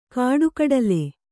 ♪ kāḍu kaḍale